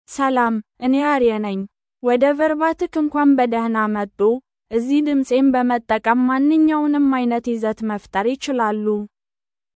AriaFemale Amharic AI voice
Aria is a female AI voice for Amharic (Ethiopia).
Voice sample
Listen to Aria's female Amharic voice.
Female
Aria delivers clear pronunciation with authentic Ethiopia Amharic intonation, making your content sound professionally produced.